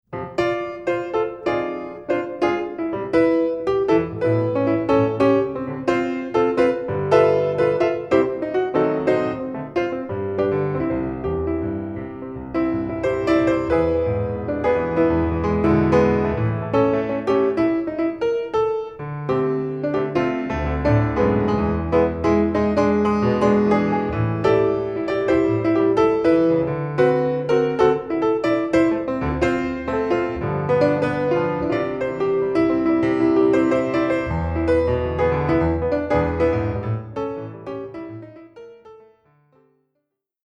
Jazz Standards & Ballads